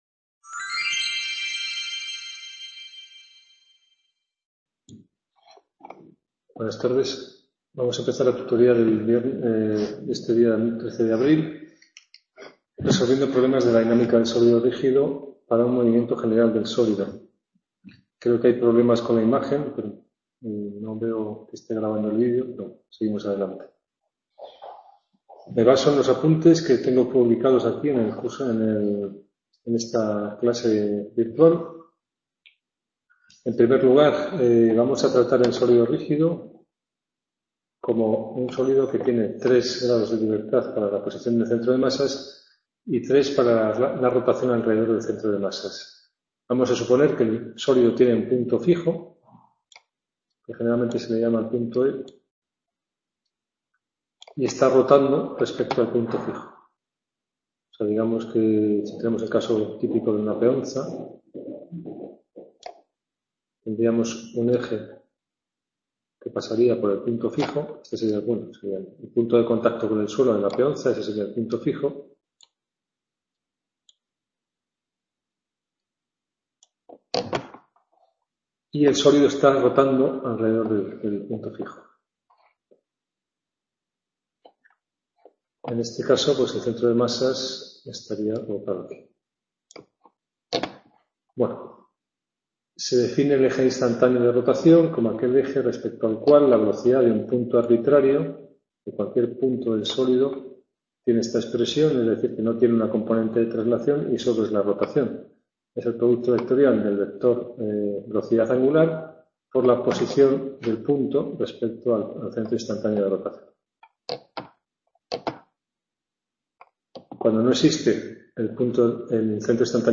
Tutoria Mecanica II - 13 Abril 2016 - movimiento… | Repositorio Digital